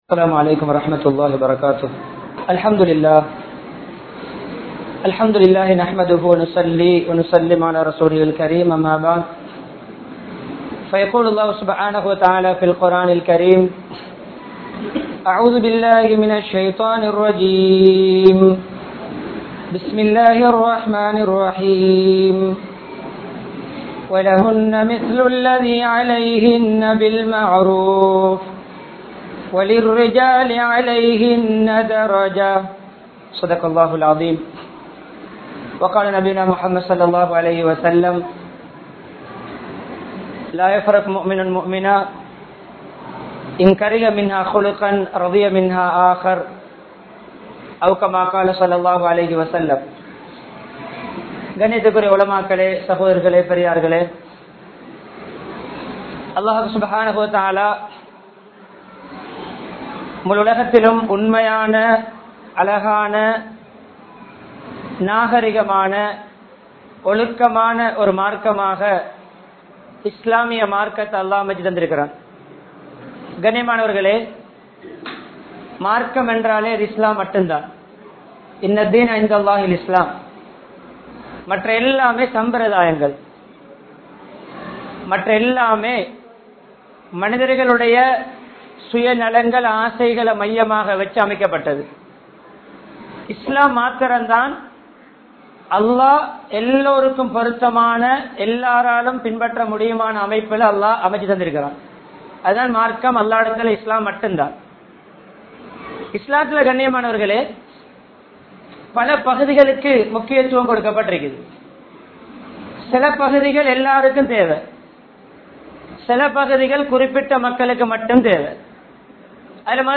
Kanavan Manaivikkidaiel Nannadaththai (கனவன் மனைவிக்கிடையில் நன்னடத்தை) | Audio Bayans | All Ceylon Muslim Youth Community | Addalaichenai
Colombo 15, Kimpulahela Jumua Masjidh